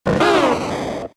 Cri de Bulbizarre K.O. dans Pokémon X et Y.